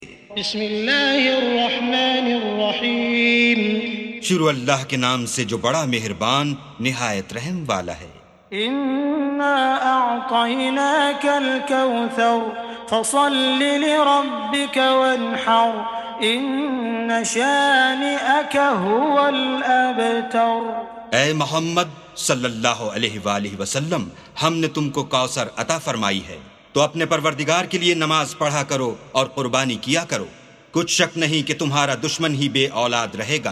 سُورَةُ الكَوۡثَرِ بصوت الشيخ السديس والشريم مترجم إلى الاردو